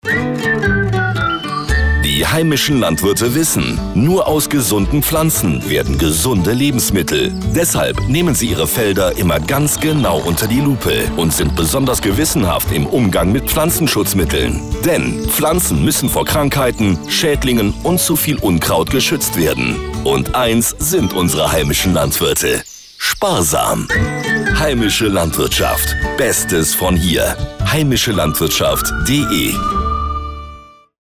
Radiospots zum Herunterladen